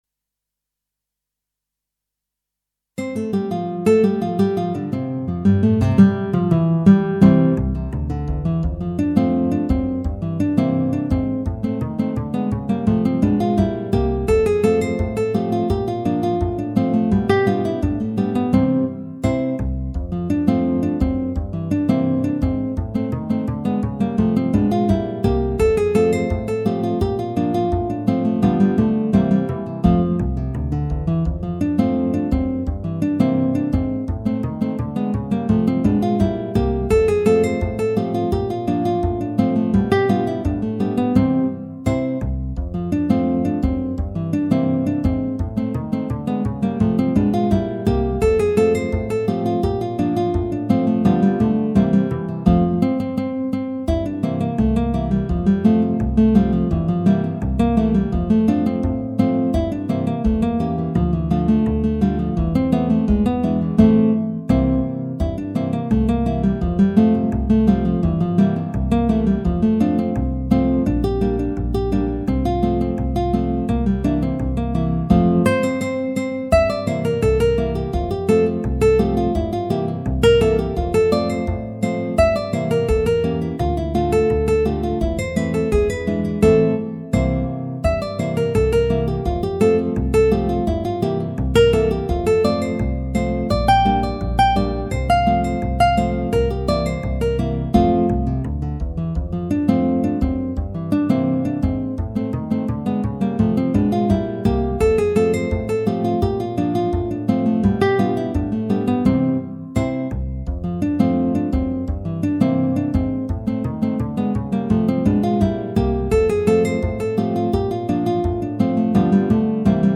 Guitar Trio
Trio arrangement of this well known rag.